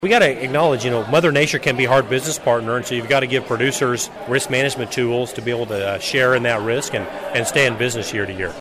Following his remarks, KMAN followed up with Rep. Mann on the 2023 Farm Bill, which is still about a year away from being finalized.